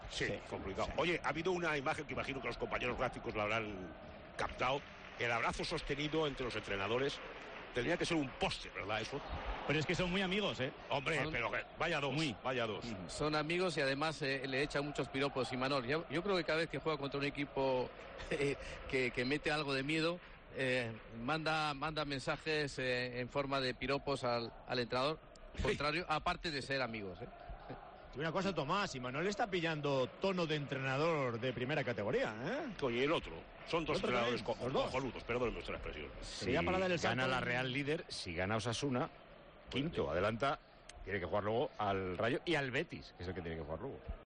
Dos apuntes interesantes los dejó de Tomás Guasch en Tiempo de Juego.